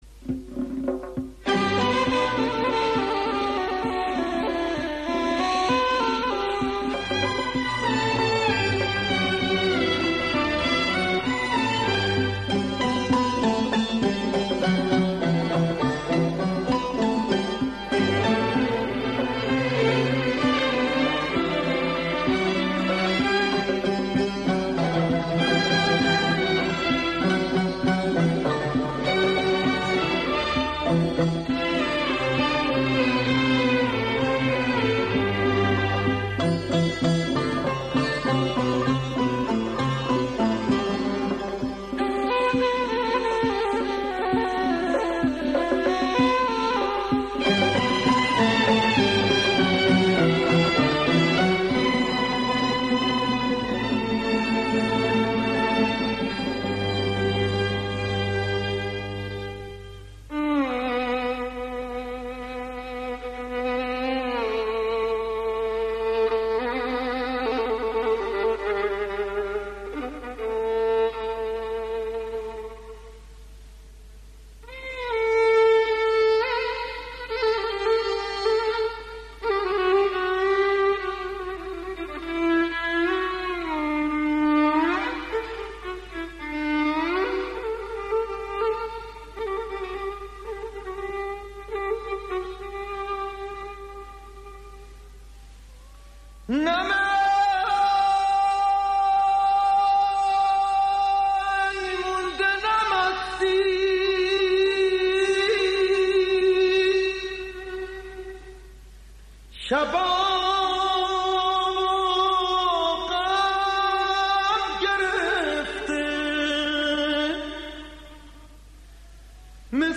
موزیک پاپ سنتی